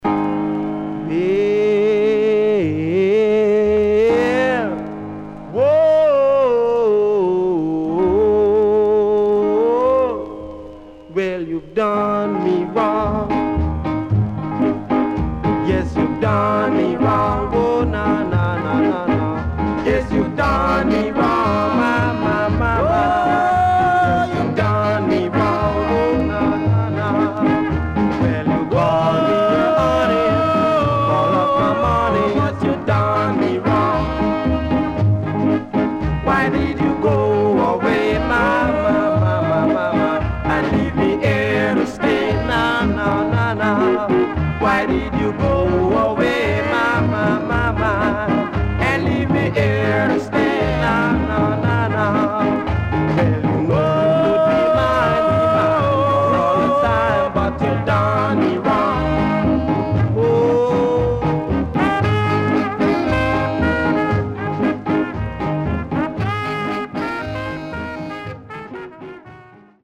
HOME > SKA
SIDE A:少しチリノイズ入りますが良好です。